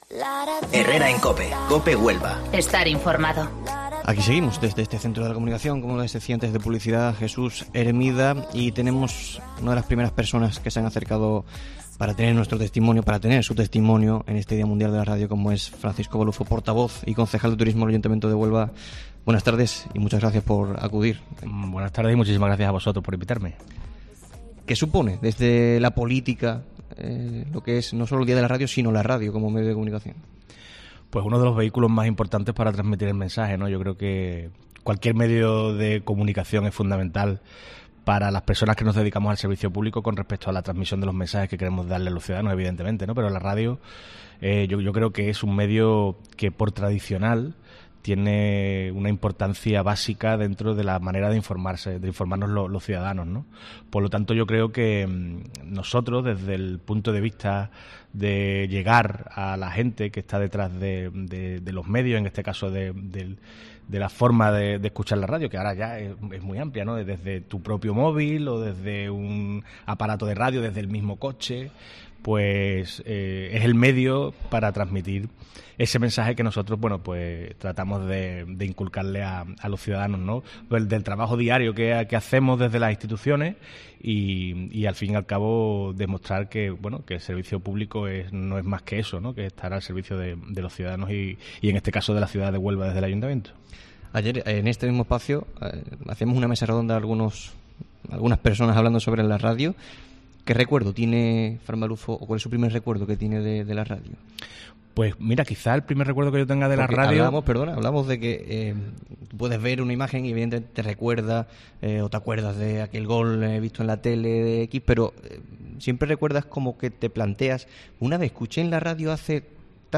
Celebramos el Día Mundial de la Radio desde el Centro de la Comunicación 'Jesús Hermida'